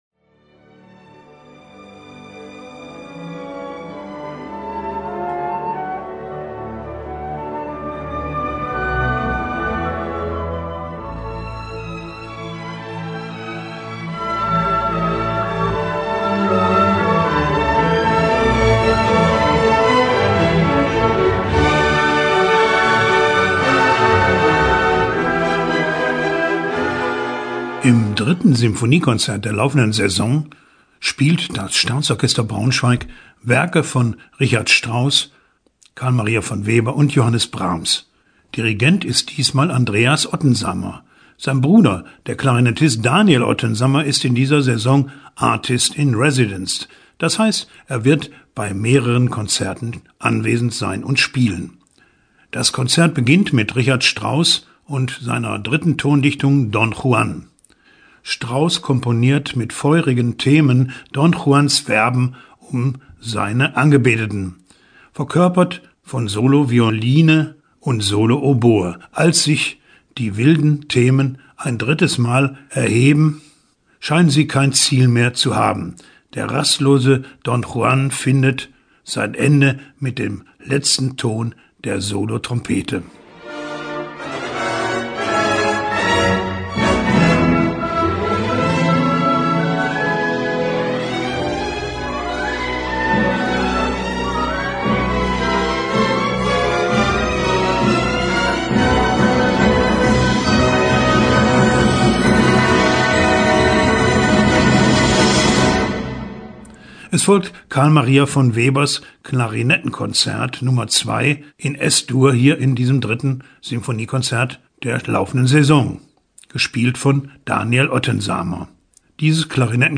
Hören Sie dazu einen Kulturtipp